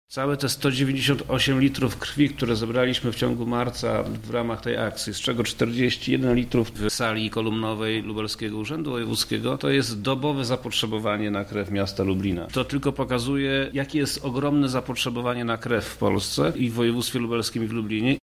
Wojewoda lubelski Przemysław Czarnek podsumował rezultaty zbiórki krwi w regionie.